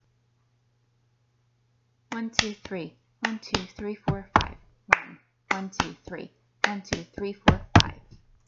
Creating a Rhythm with Body Percussion
Here is 3-5-1-3-5:
Rhythm-Pattern-3-5-1-3-5.m4a